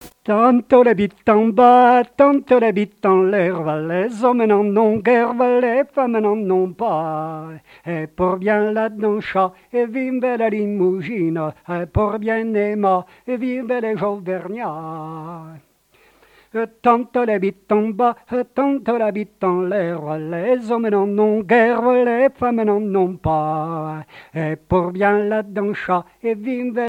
Chants brefs - A danser
danse : bourree
répertoire de chansons et airs à l'accordéon
Pièce musicale inédite